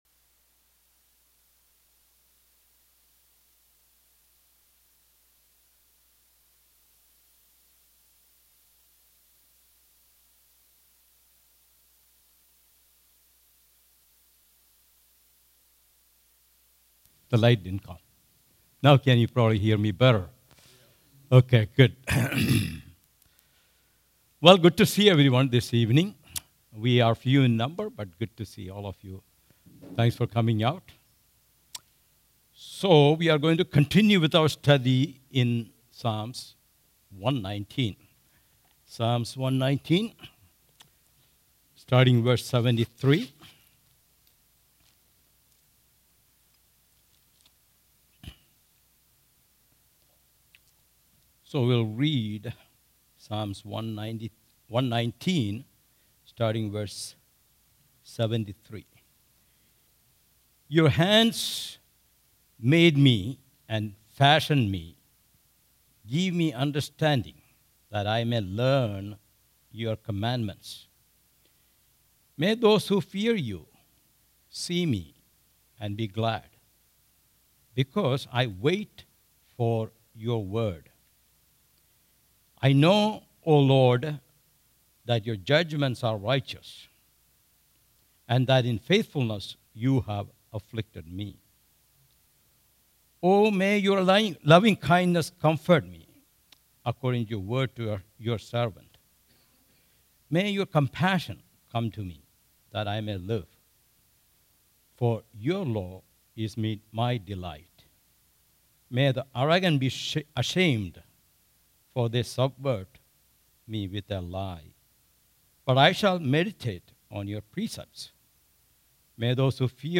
All Sermons Psalm 119:73-80